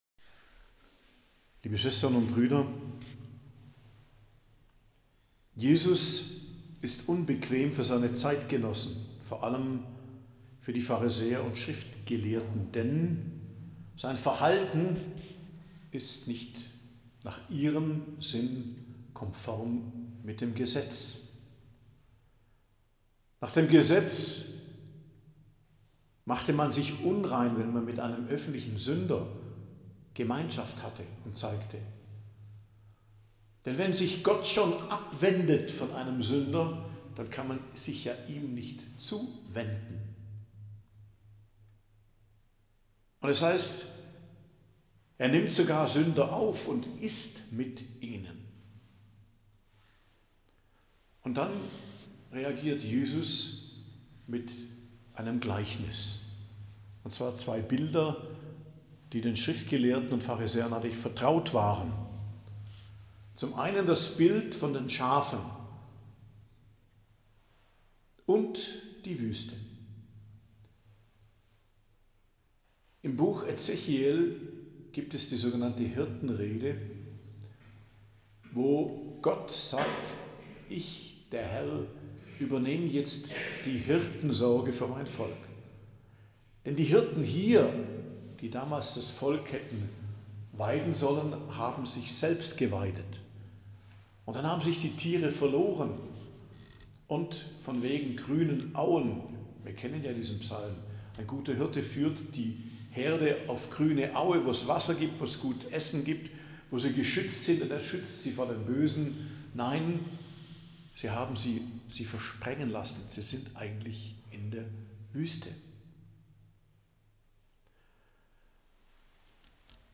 Predigt am Donnerstag der 31. Woche i.J. 7.11.2024